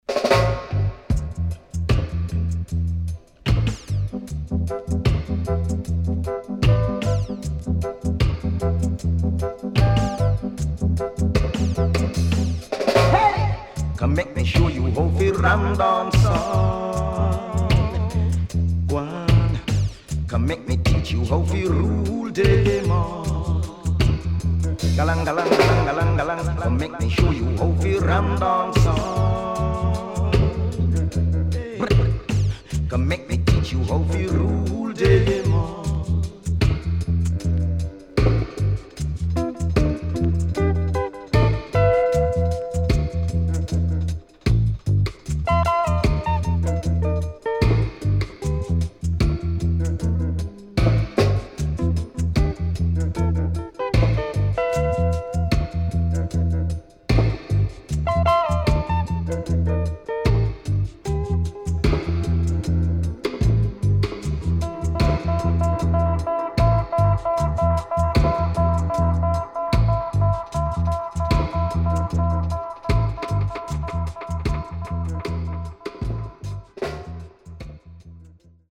HOME > REISSUE [DANCEHALL]